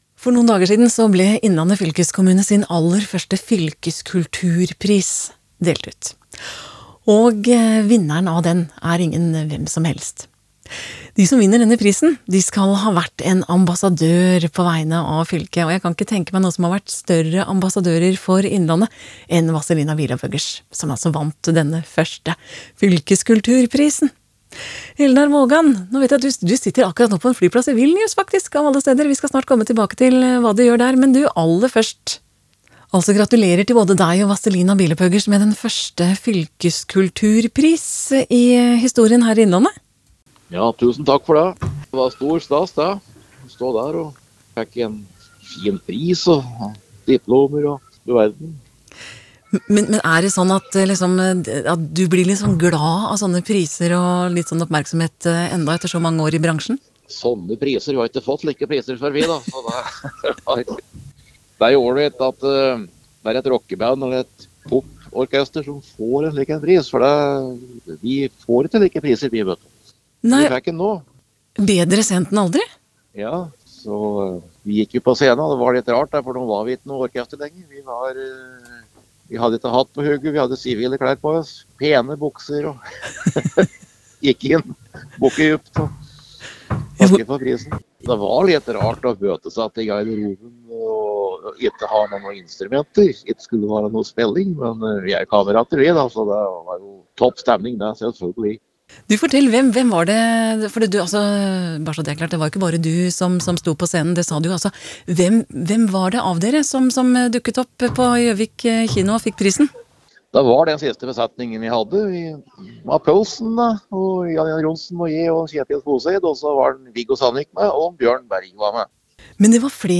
Innslag
Eldar Vågan som tok imot prisen på vegne av hele bandet forteller at det var litt rart å stå på en scene igjen uten instrumenter og i sivile klær.